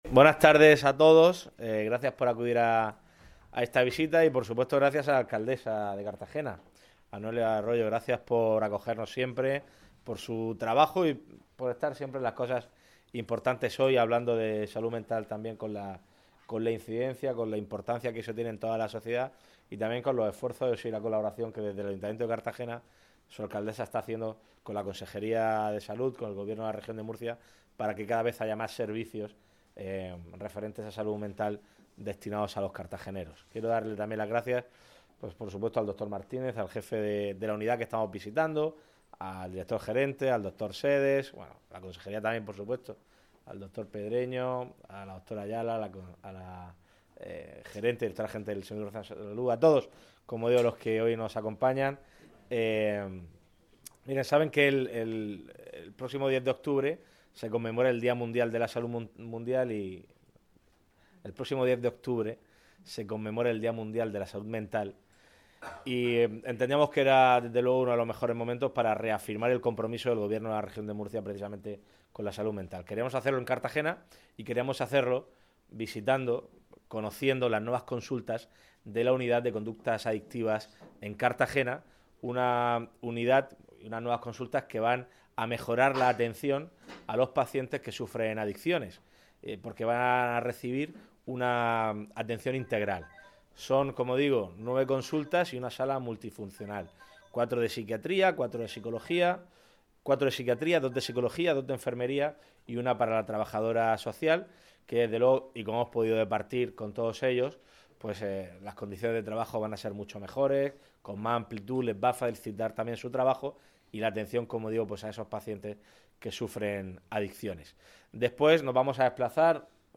Audio: Declaraciones de Fernando L�pez Miras y Noelia Arroyo.